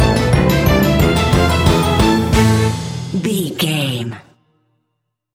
Fast Silly Halloween Music Stinger.
Aeolian/Minor
ominous
eerie
strings
brass
synthesiser
percussion
piano
horror music